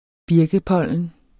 Udtale [ ˈbiɐ̯gə- ]